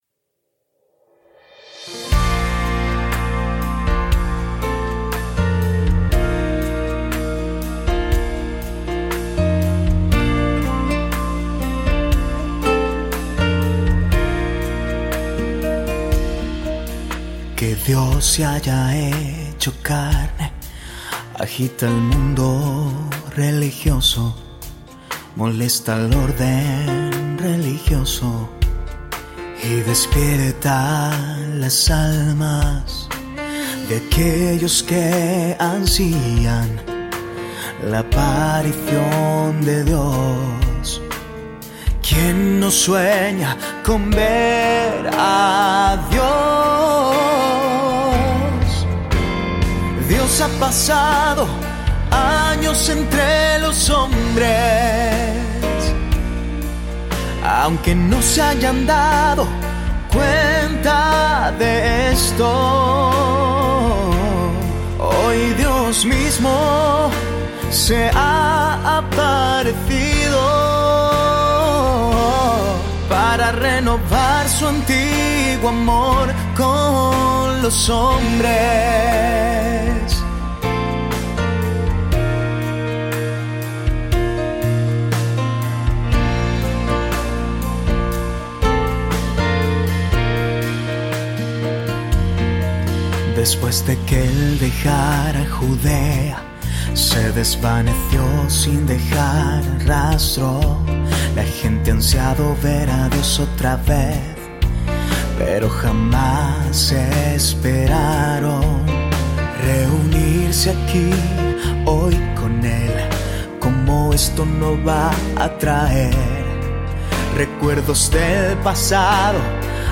Categories: Hymns of God's Words